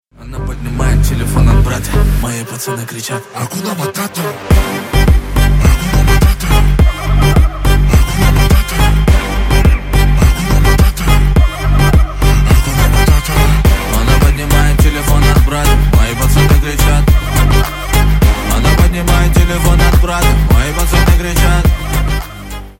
Рэп Хип-Хоп Рингтоны